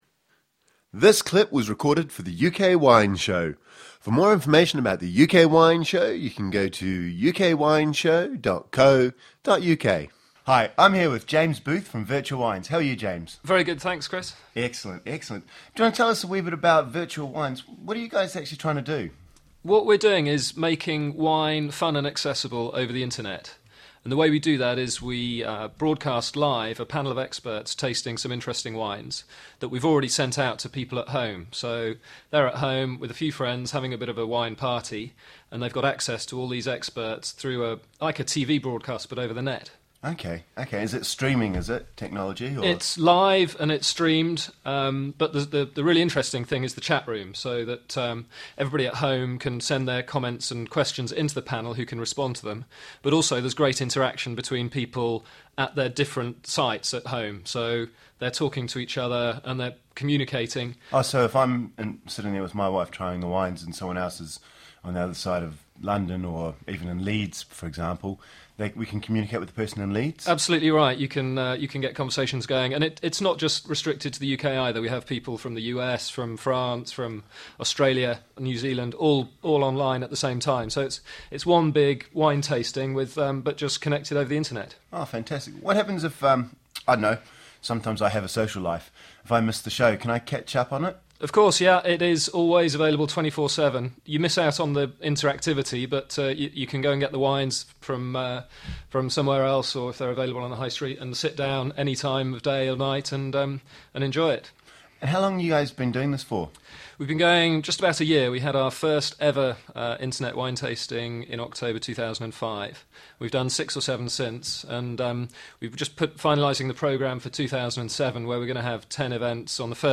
The music used for the UK Wine Show is Griffes de Jingle 1 by Marcel de la Jartèle and Silence by Etoile Noire.